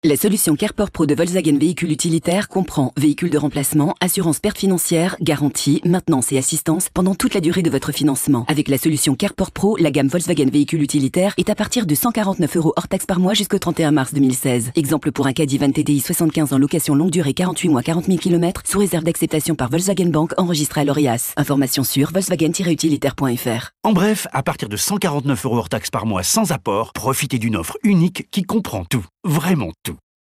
sehr variabel
Mittel minus (25-45)
Commercial (Werbung), Lip-Sync (Synchron)